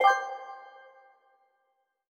Correct Sound 5.ogg